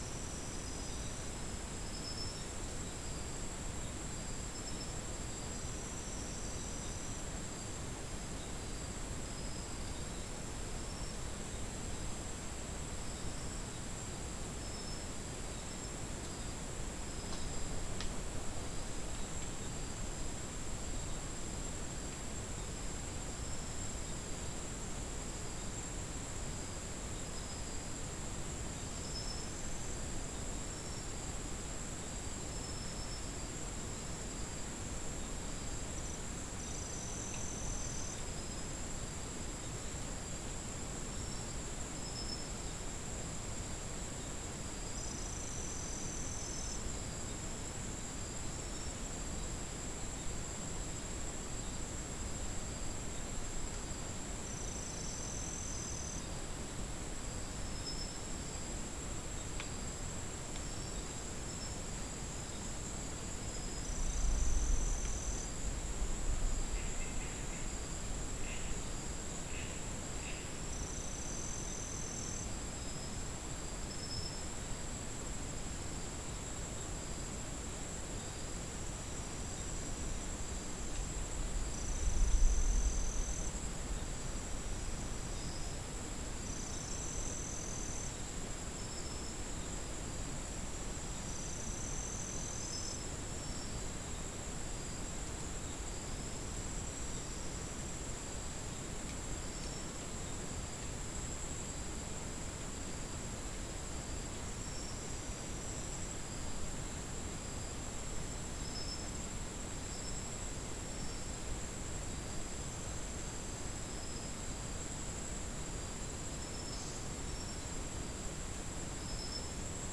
Non-specimen recording: Soundscape Recording Location: South America: Guyana: Mill Site: 1
Recorder: SM3